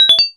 menuback.wav